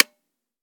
weap_uzulu_disconnector_plr_01.ogg